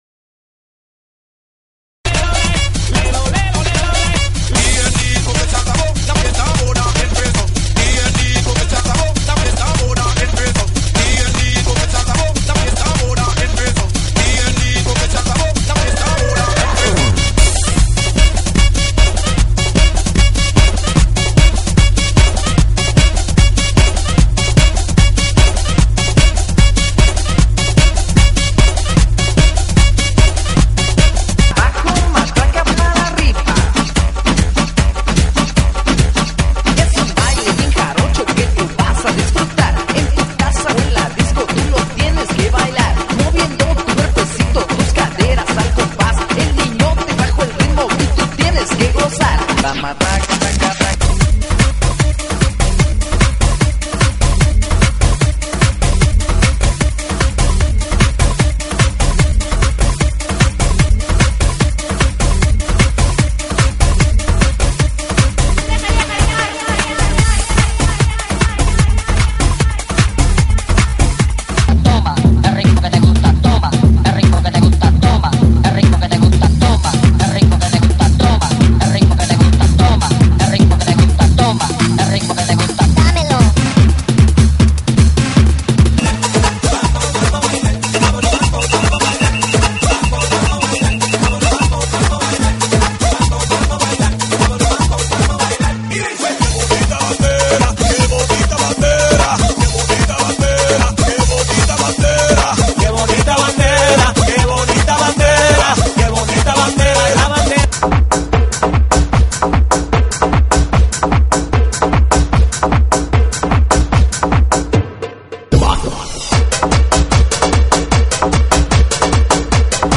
GENERO: ELECTRONICO – LATINO – TRIBAL
ELECTRONICA, LATIN, TRIBAL,